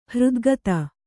♪ hřdgata